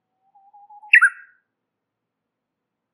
우구이스2
warbler2.mp3